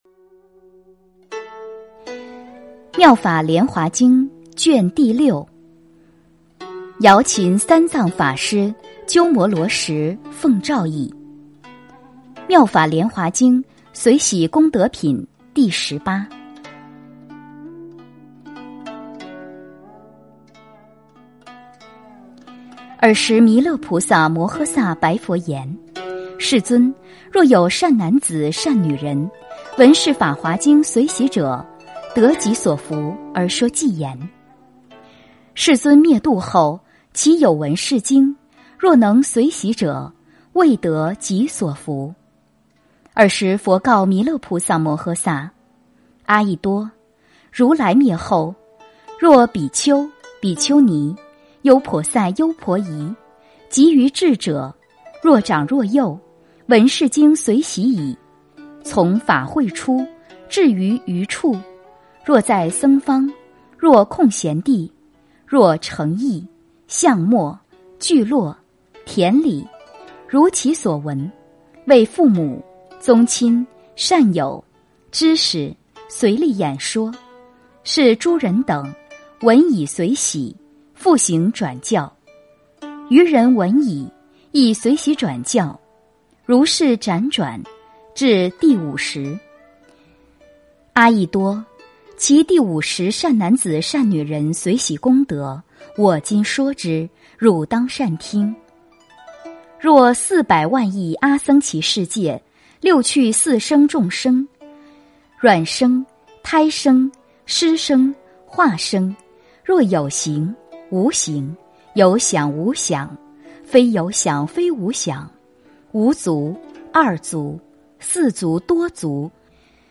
《妙法莲华经》随喜功德品第十八--佚名 经忏 《妙法莲华经》随喜功德品第十八--佚名 点我： 标签: 佛音 经忏 佛教音乐 返回列表 上一篇： 《妙法莲华经》分别功德品第十七--佚名 下一篇： 《妙法莲华经》常不轻菩萨品第二十--佚名 相关文章 杨枝净水赞--天籁之音 杨枝净水赞--天籁之音...